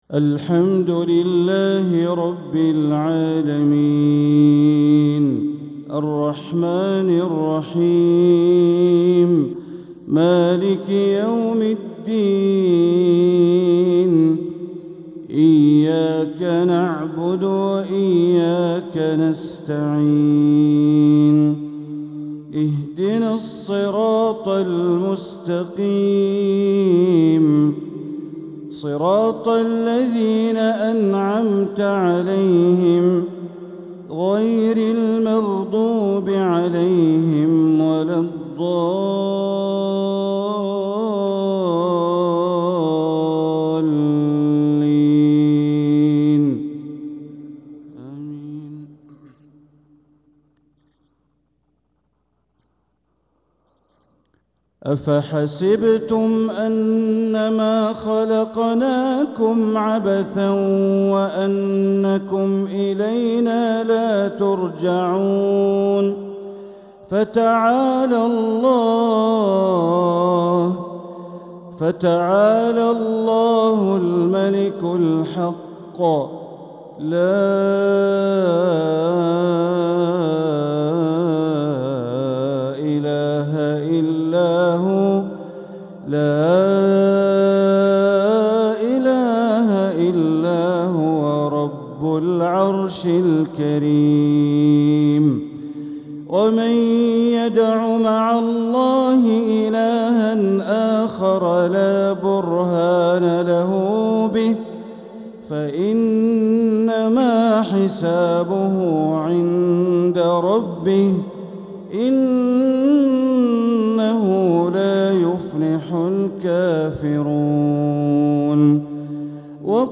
مغرب الجمعة 30 محرم 1447هـ | خواتيم سورتي المؤمنون 115-118 و القيامة 36-40 > 1447هـ > الفروض - تلاوات بندر بليلة